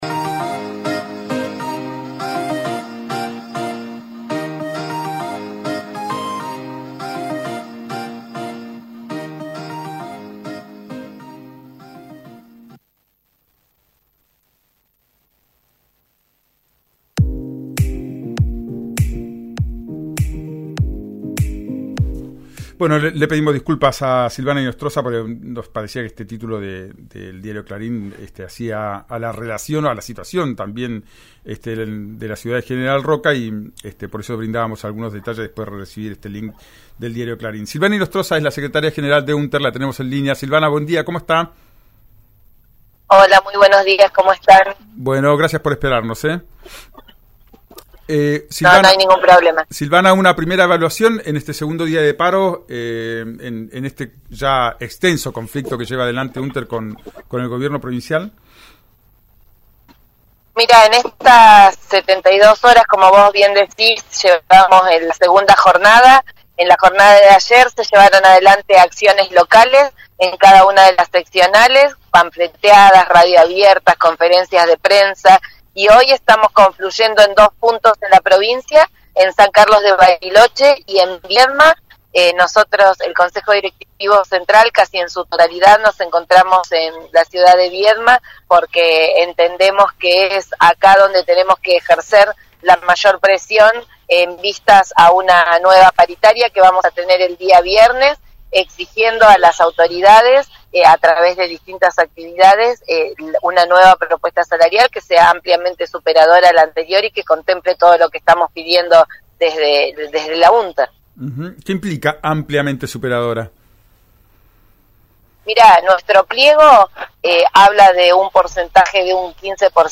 respondió en diálogo con RÍO NEGRO RADIO. Conocé las repercusiones sobre los dichos de la mandataria y cómo continuarán las medidas de fuerza.